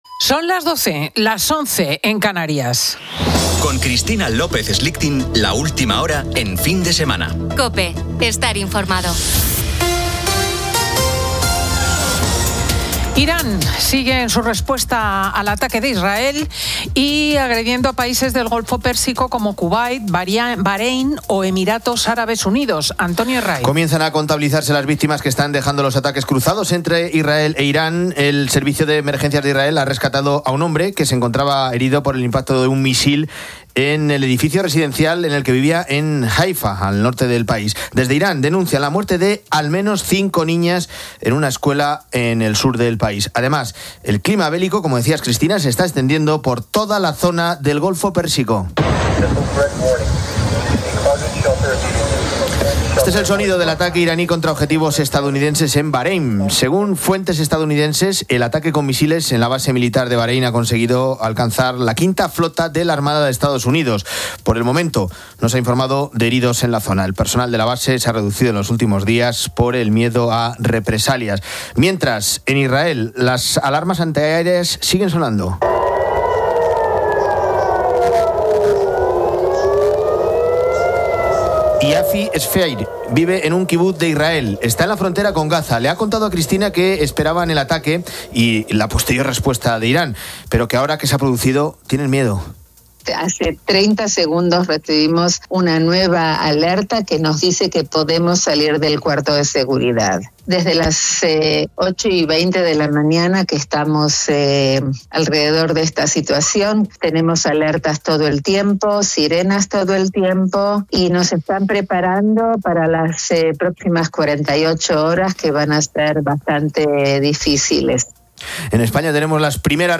Ángel Expósito, director de La Linterna, analiza la escalada bélica en Oriente Próximo.
Y Carmen Lomana repasa la actualidad social Redacción Digital 28 feb 2026, 13:10 - 59:00 min Descargar Facebook Twitter Whatsapp Telegram Enviar por email Copiar enlace